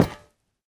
1.21.5 / assets / minecraft / sounds / block / vault / step2.ogg